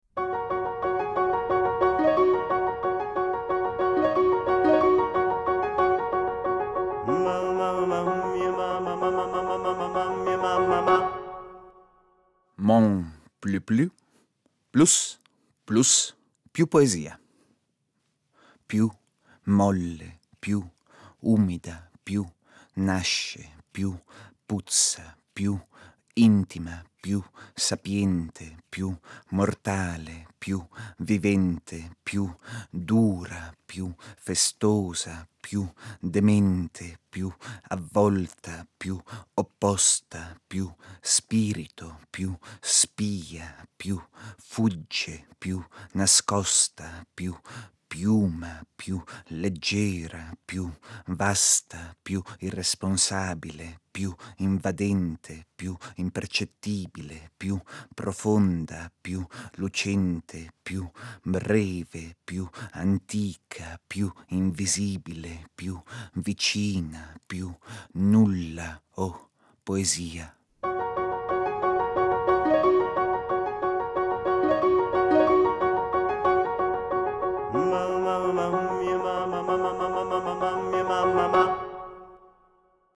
Poesia